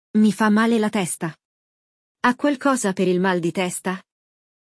Neste episódio, acompanhamos uma conversa entre um senhor e a atendente da farmácia, onde ele explica seu problema e recebe orientações sobre os medicamentos disponíveis.
Durante o episódio, você ouvirá o diálogo e terá momentos dedicados à repetição, para aperfeiçoar sua pronúncia.